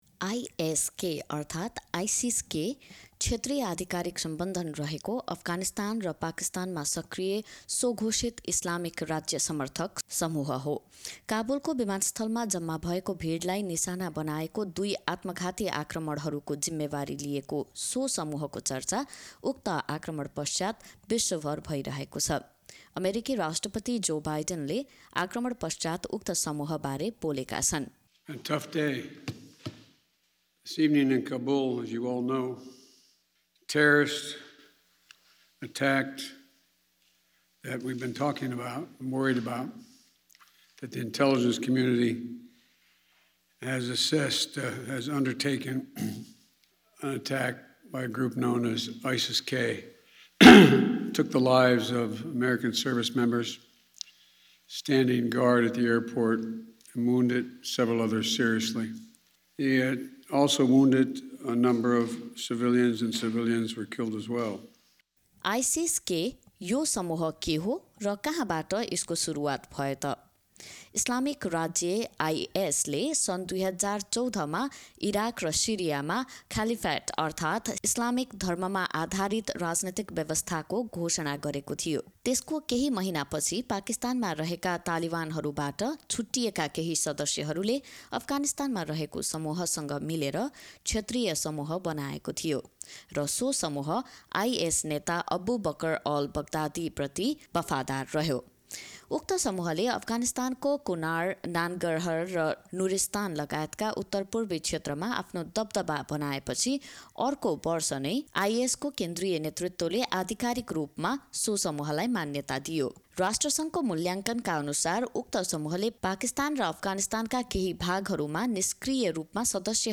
काबुल विमानस्थलमा आक्रमण गर्ने समूह आइएस-के तालिबान भन्दा धेरै क्रूर भएको बताइएको छ। आइसिस-के अर्थात् इस्लामिक स्टेट खोरोसानका नामले पनि चिनिने उक्त समूह के हो र कहाँ बाट आएको हो भन्ने बारे एक रिपोर्ट सुनौँ।